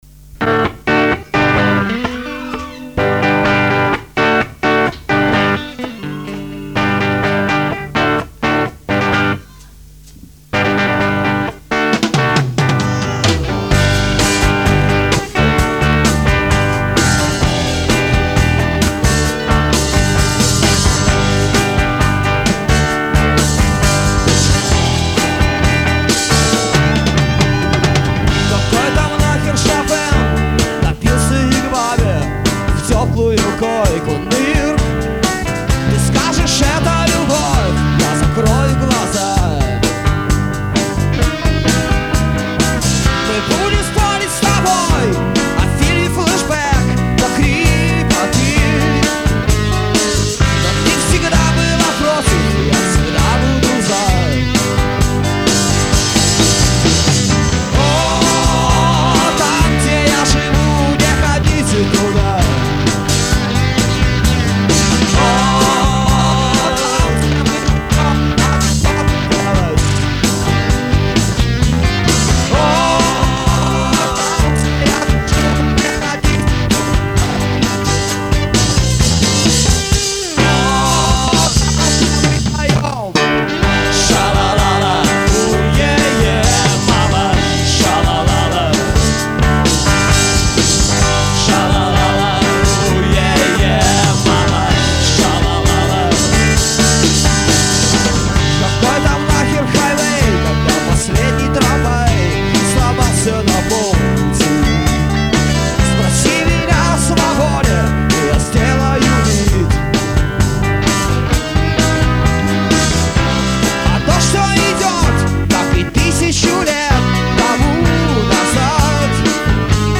вокал, ак.гитара
ударные